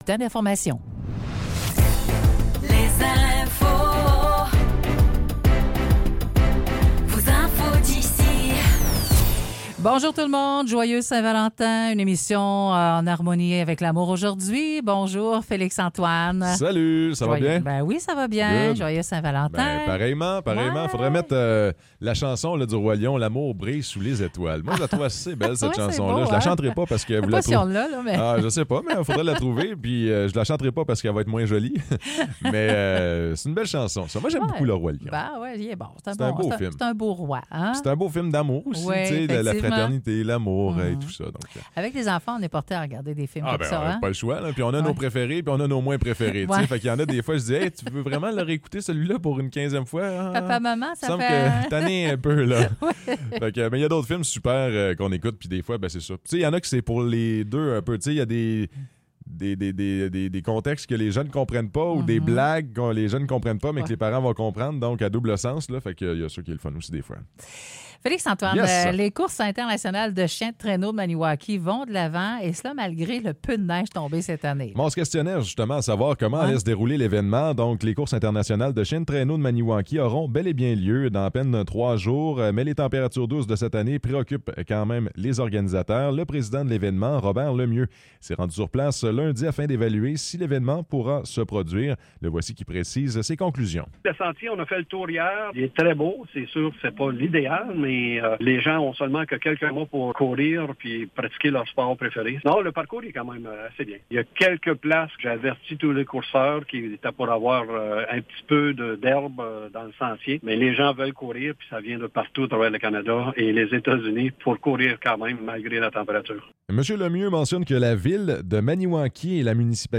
Nouvelles locales - 14 février 2024 - 9 h